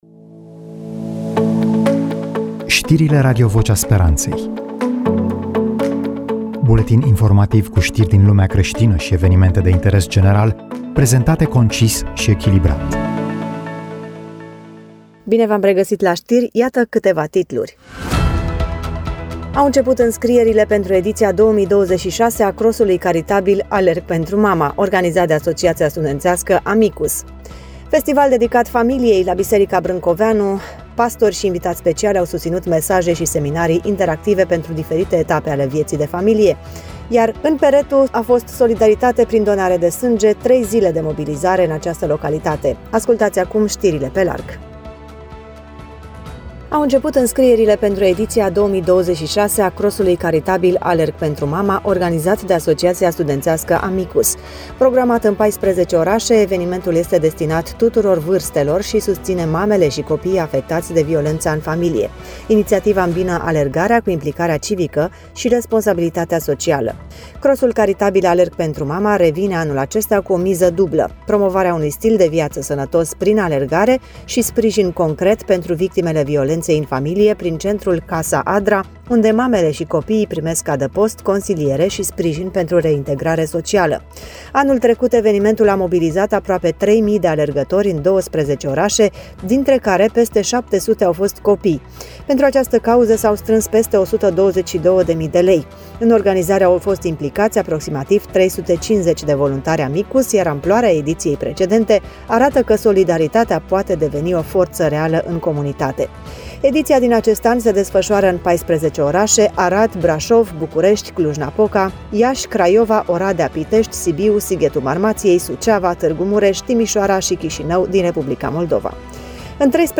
EMISIUNEA: Știri Radio Vocea Speranței